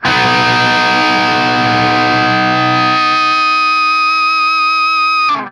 TRIAD B   -L.wav